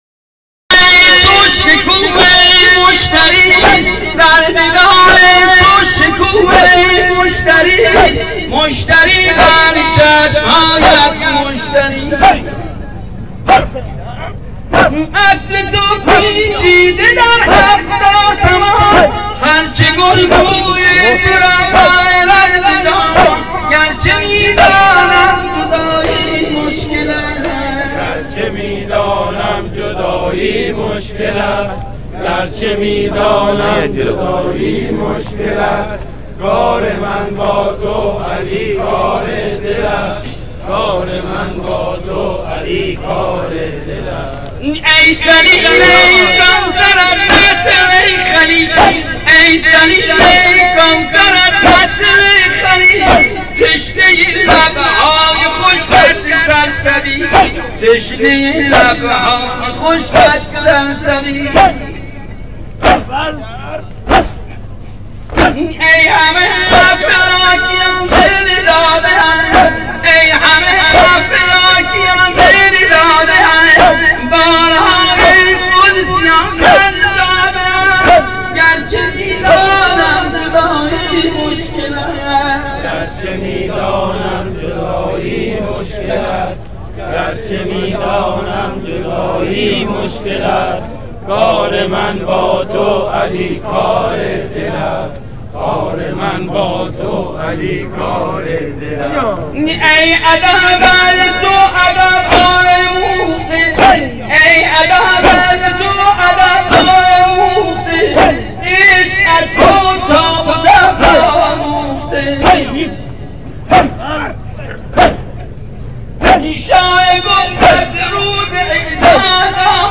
صوت/سینه زنی لاری – شب هشتم محرم
چرا نمیشه دانلود کرد، خیلی زیبا و دلنشین میخونه لطفاً کارب کنید که بتونیم دانلود کنیم ، با تشکر